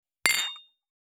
332食器,テーブル,チーン,カラン,キン,コーン,チリリン,カチン,チャリーン,クラン,カチャン,クリン,シャリン,チキン,コチン,カチコチ,チリチリ,シャキン,
コップワイン効果音厨房/台所/レストラン/kitchen室内食器
コップ